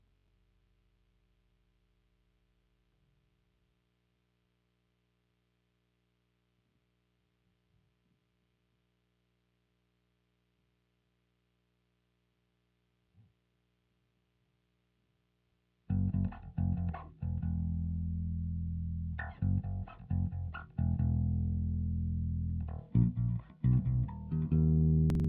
Bass_018.wav